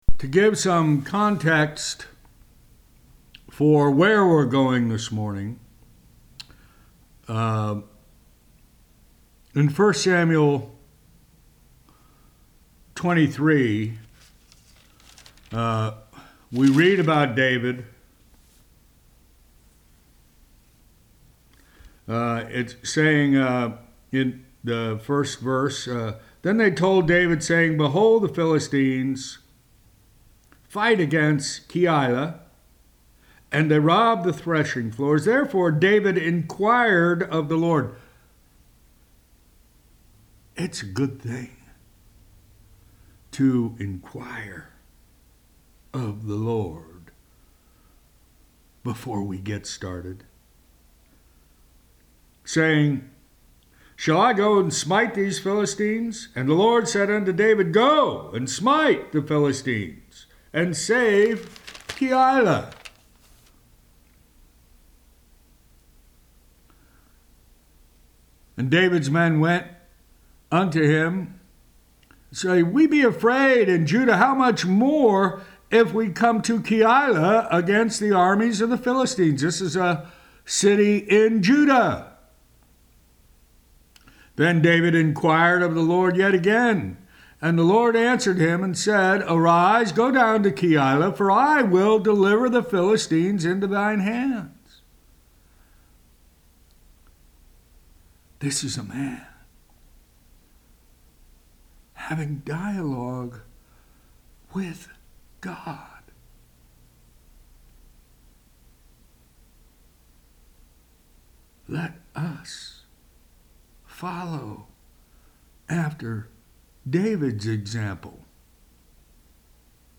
Weekly Teaching